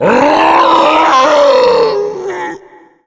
zombie_die2.wav